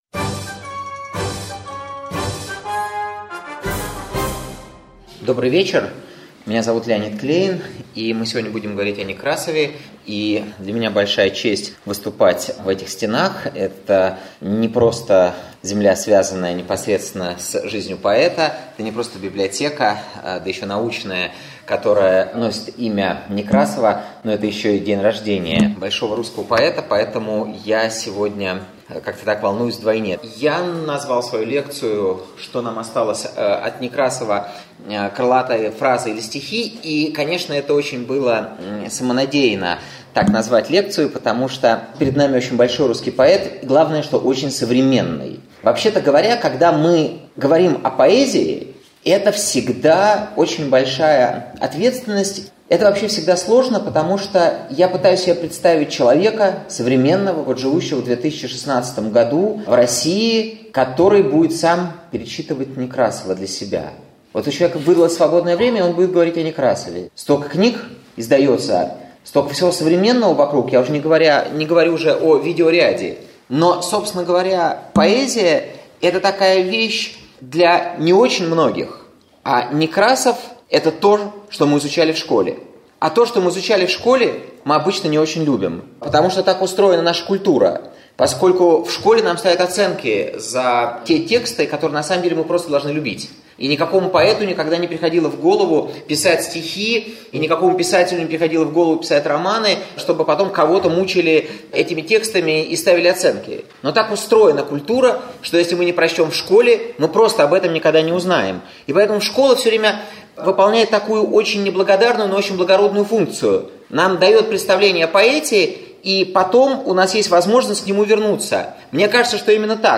Аудиокнига Некрасов. Лирика.